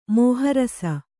♪ mōha rasa